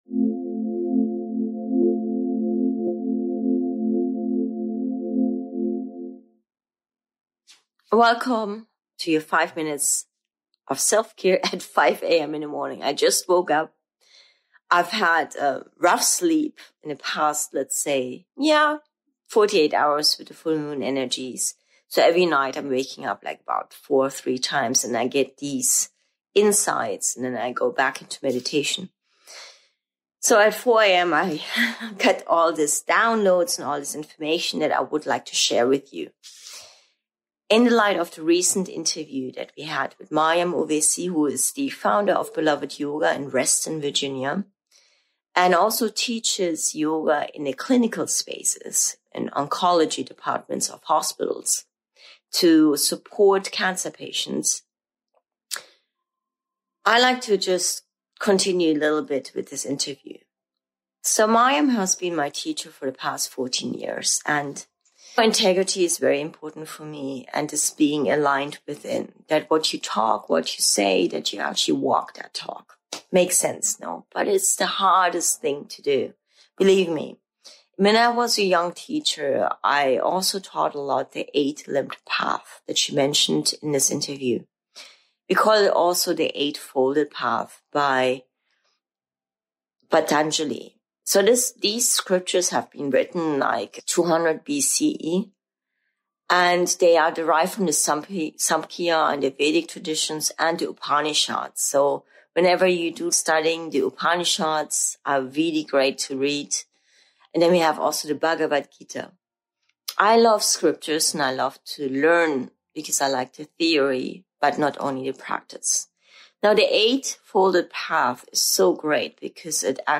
Dive into an inspiring conversation